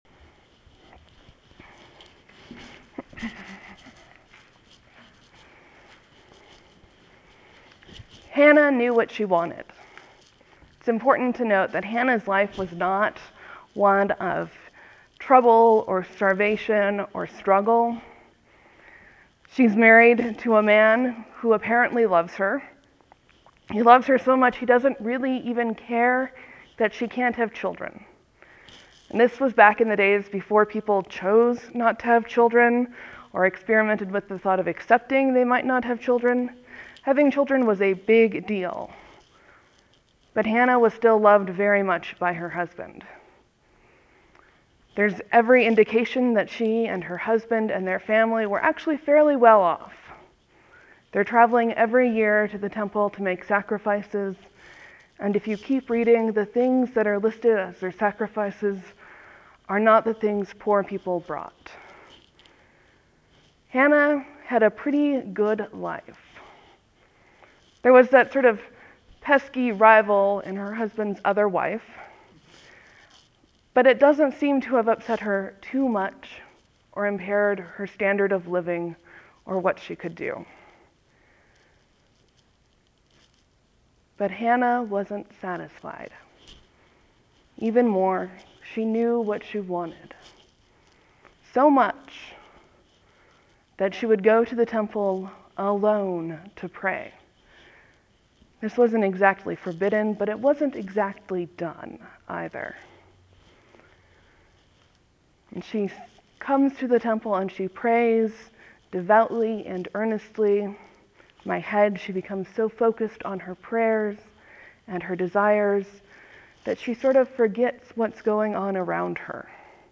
(There will be a few moments of silence before the sermon begins.